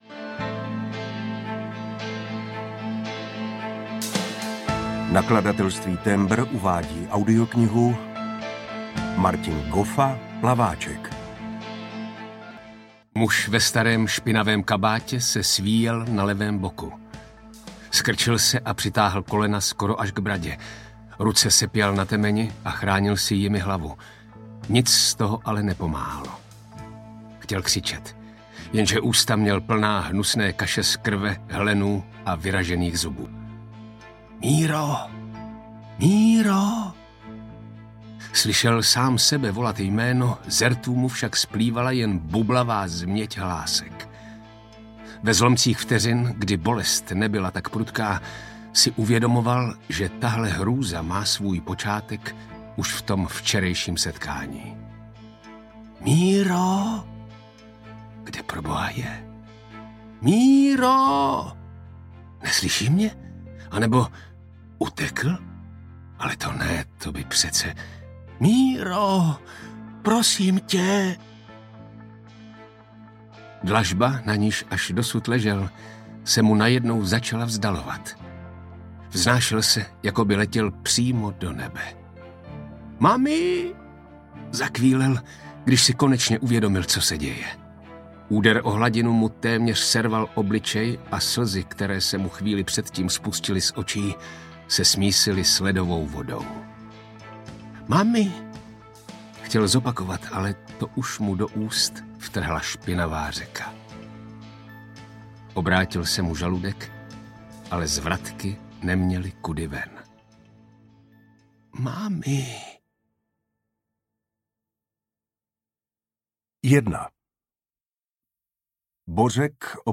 Plaváček audiokniha
Ukázka z knihy